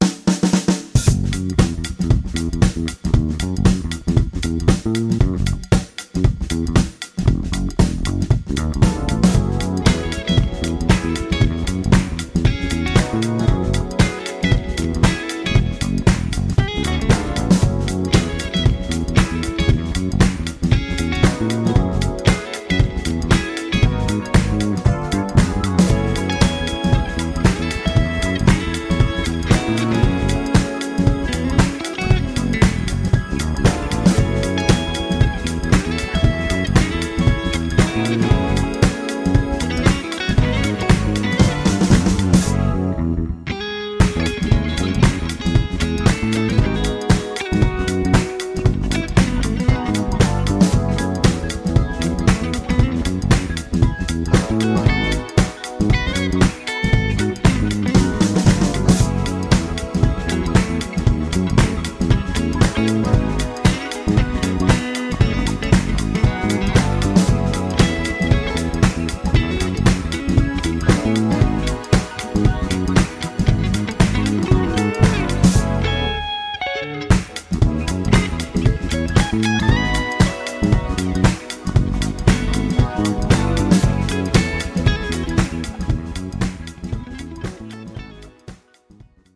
ジャンル(スタイル) JAZZ / FUNK / DISCO / CROSSOVER